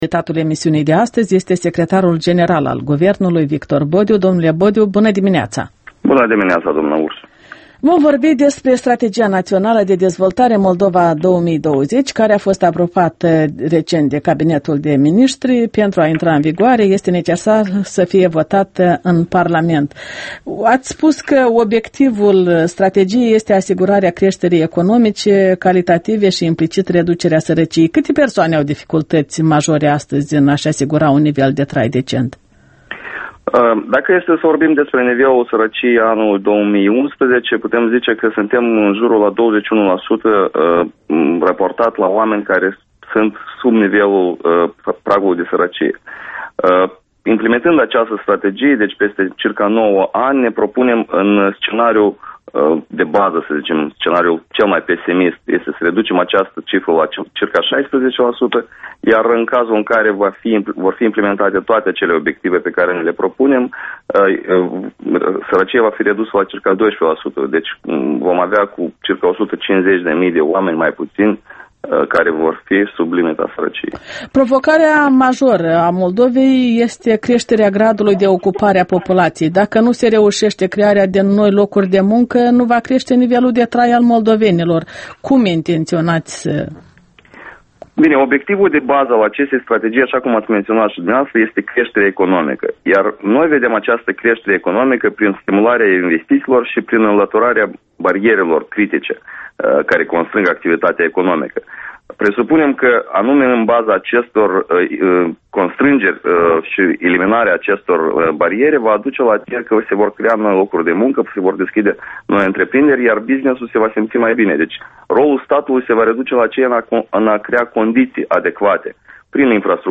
Interviul dimineții la Europa Liberă: cu Victor Bodiu despre strategia „Moldova 2020”